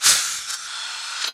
laserRecharge.wav